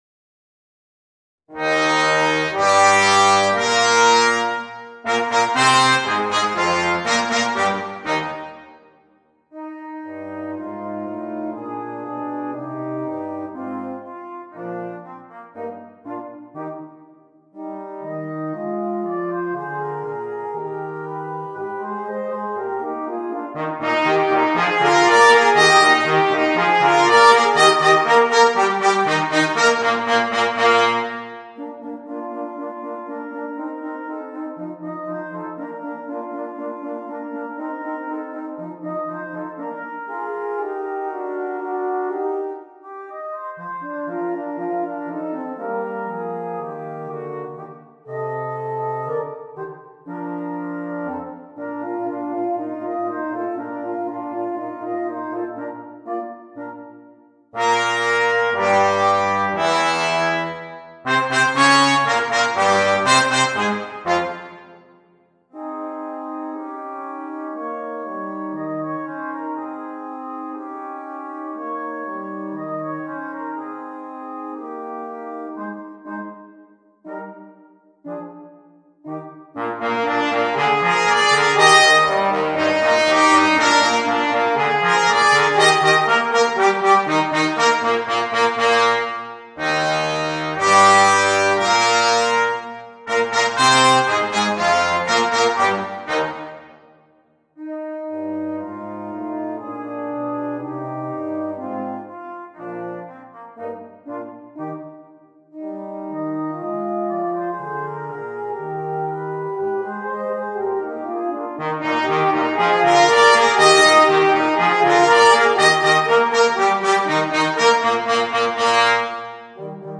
für Trompete, Horn und Posaune
Ensemblemusik für 3 Blechbläser